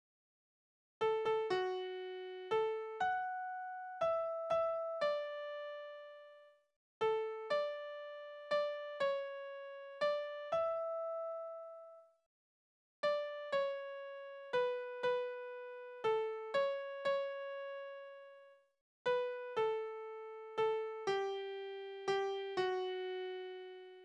« O-5645 » Eine Heldin wohlerzoge Balladen: Die unglückliche Schützin Eine Heldin wohlerzogen mit Namen Isabell.
Tonart: D-Dur Taktart: 3/4 Tonumfang: Oktave Externe Links